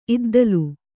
♪ iddalu